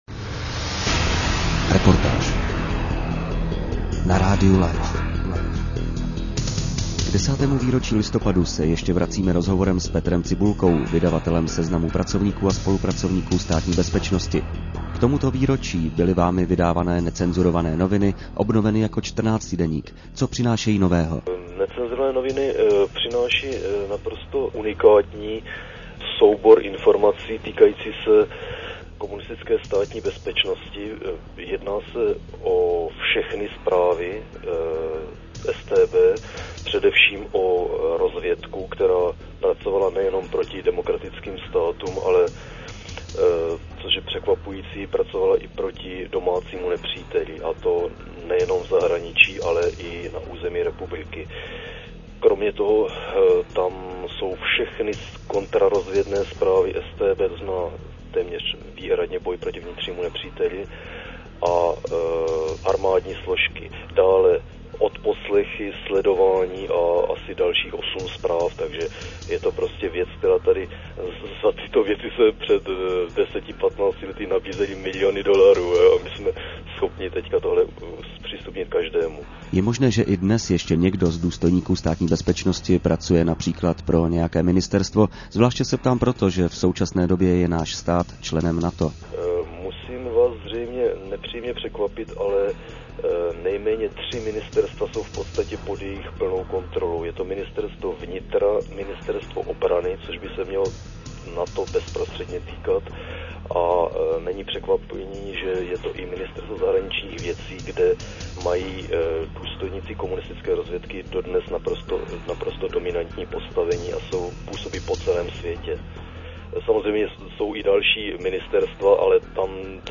ZVUKOV� Z�ZNAM ROZHOVORU